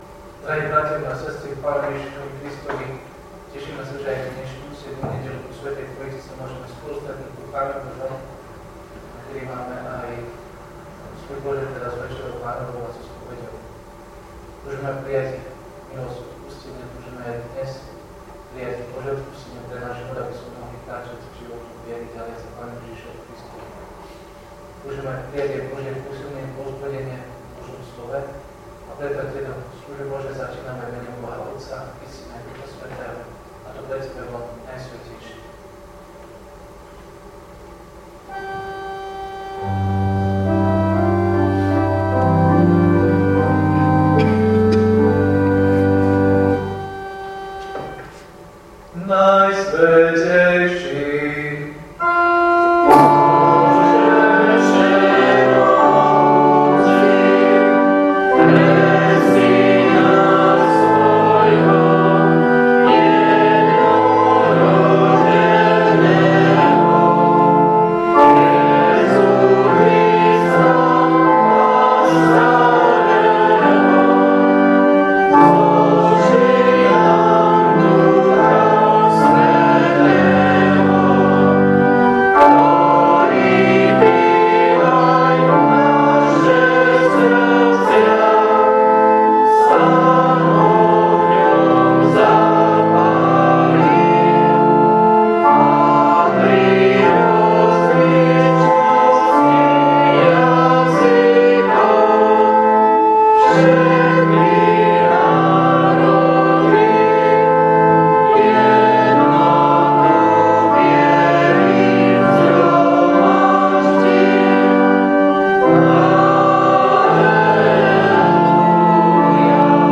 Služby Božie – 7. nedeľa po Sv. Trojici
V nasledovnom článku si môžete vypočuť zvukový záznam zo služieb Božích – 7. nedeľa po Sv. Trojici.
PIESNE: 192, 245, 297, 304, A88.